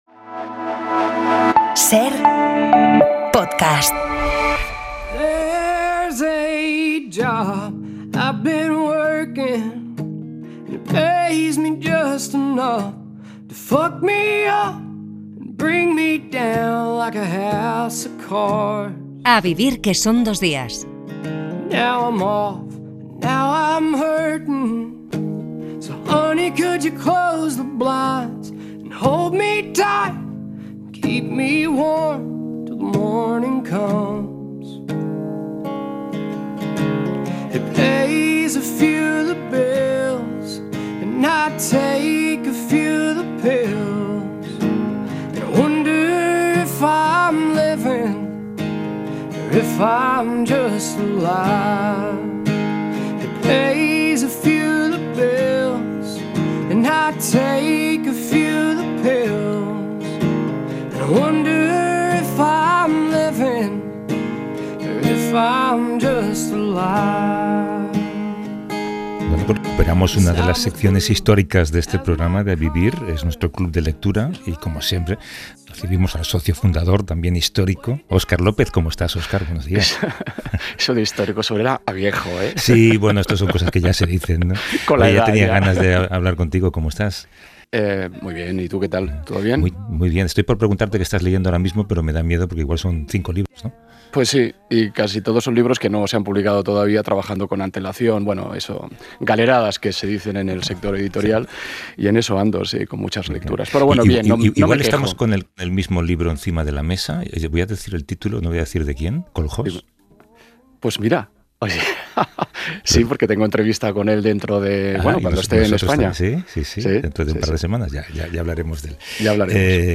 Junto a Óscar López, recibimos a Luis Landero, que acaba de publicar en Tusquets "Coloquio de invierno". Siete personajes se quedan aislados en una casa rural a causa de la borrasca Filomena. Aprovechan esa circunstancia para contar historias al calor de la chimenea.